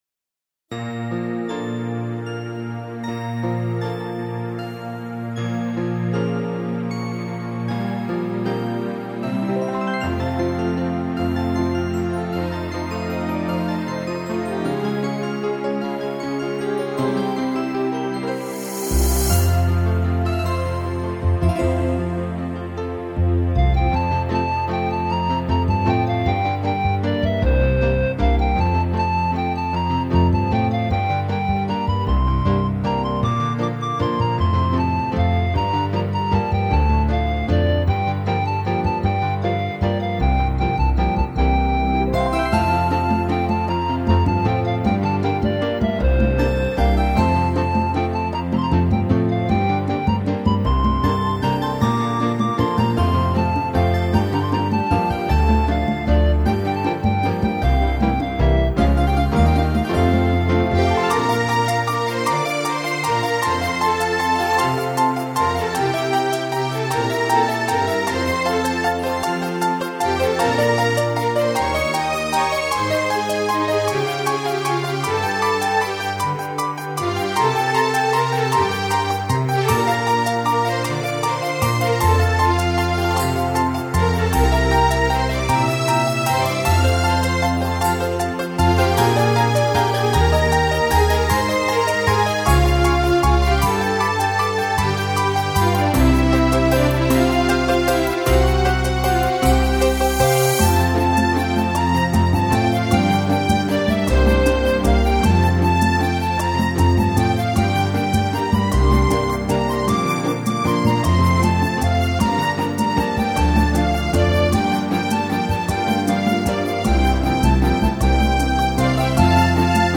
I thought I'd go back to my ambient roots on this one.